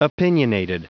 Prononciation du mot opinionated en anglais (fichier audio)
Prononciation du mot : opinionated